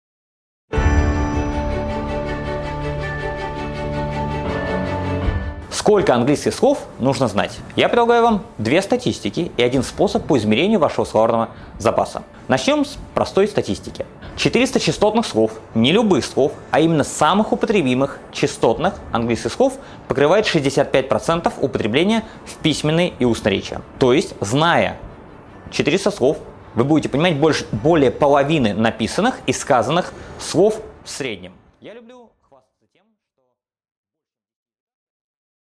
Аудиокнига Сколько английских слов нужно знать? Для социализации, чтобы учиться и работать за границей | Библиотека аудиокниг